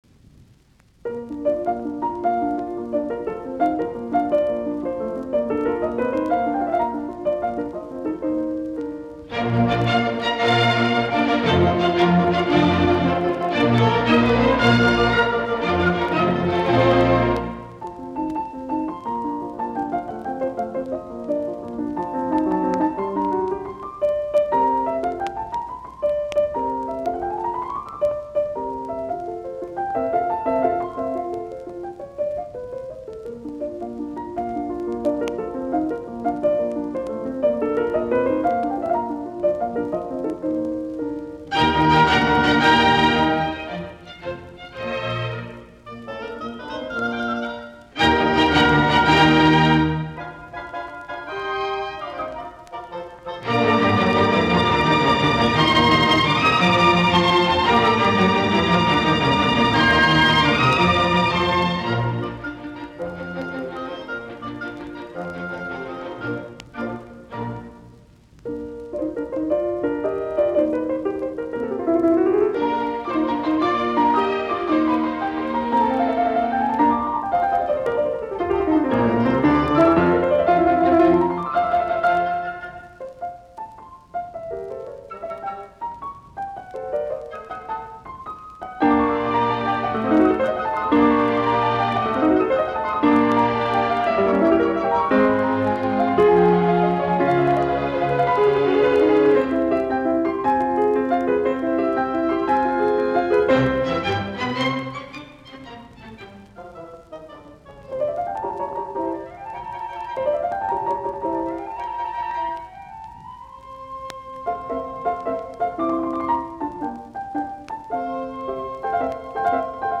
Soitinnus: Piano, ork.